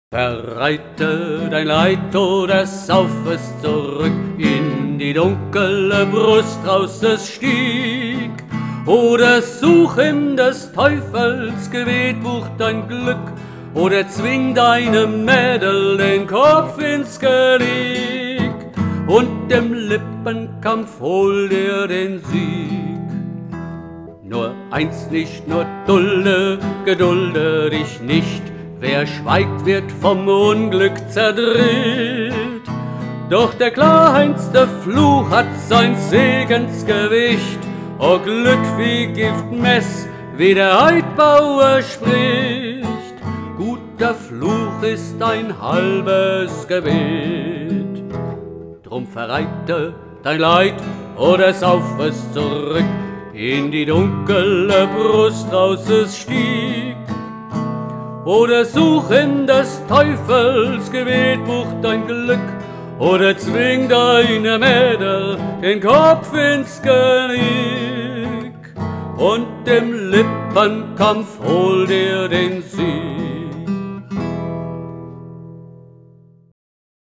Die Hörprobe stammt von einer Aufnahme aus dem Jahr 2011.
solo_der-trost-des-obristen_voll.mp3